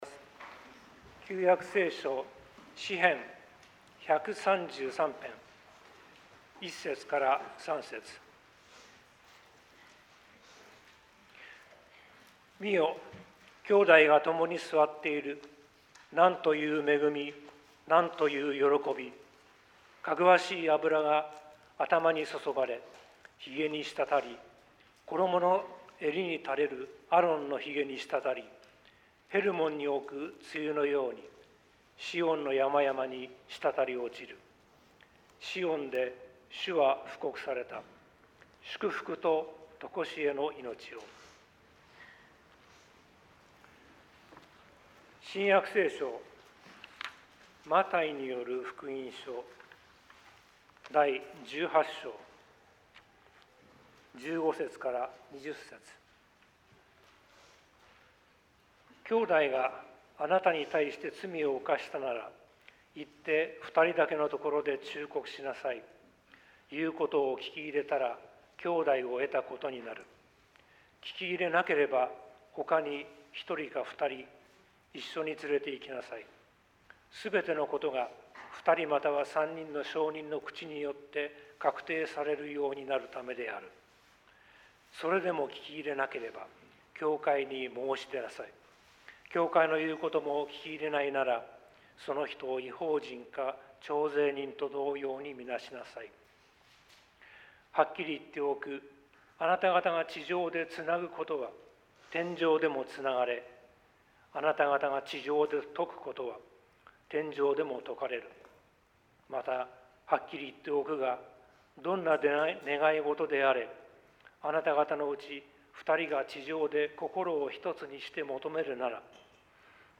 説教